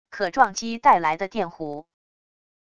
可撞击带来的电弧wav音频